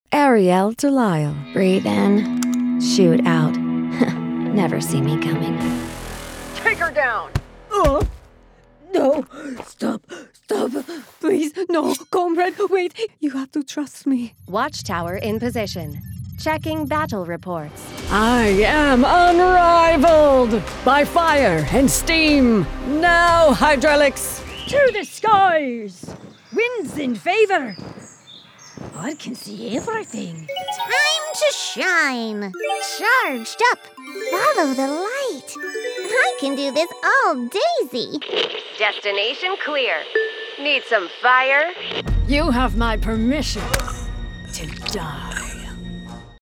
veteran voice actor, upbeat commercial branding voice & audiobook narrator.